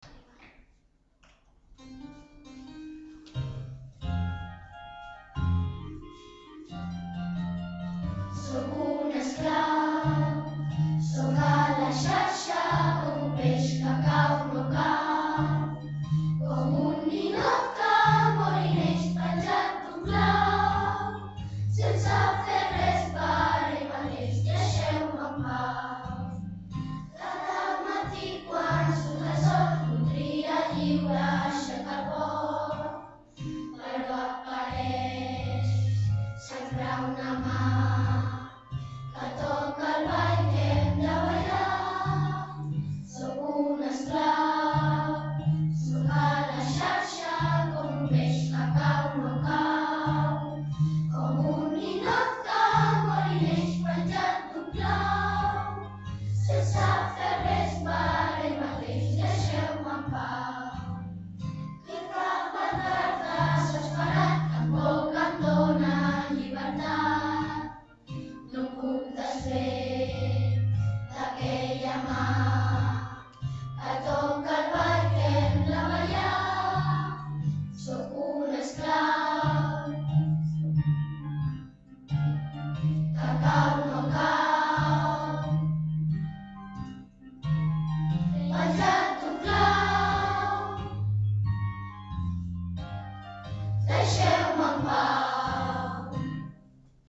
A continuació us posem totes les cançons de la cantata el Ratolí Electrònic text d’en Jesús Nieto i música d’en Ricard Gimeno que els alumnes de 4t hem enregistrat a l’escola .